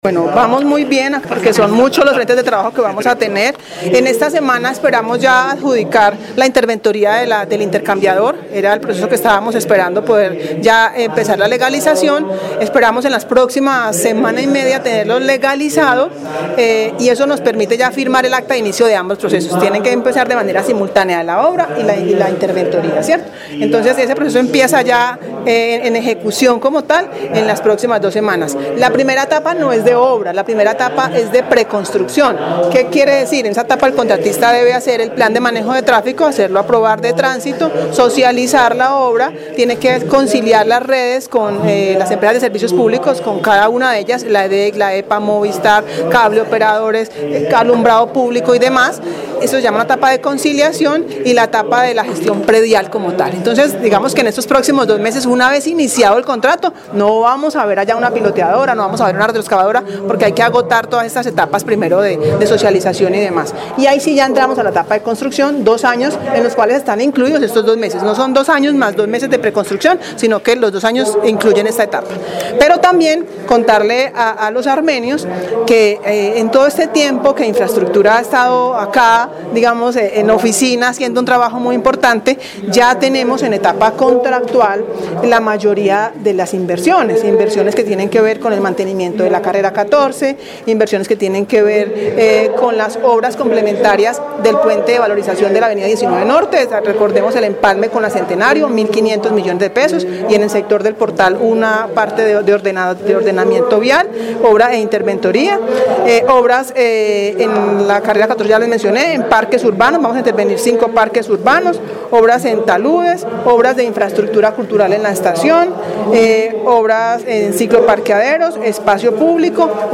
Claudia Arenas, secretaria de infraestructura de Armenia
En el noticiero del mediodía de Caracol Radio Armenia hablamos con Claudia Arenas secretaria de infraestructura de la alcaldía de Armenia que entregó detalles sobre cómo avanzan los procesos de adjudicación de interventoría y contratación de las obras de infraestructura en la capital del Quindío.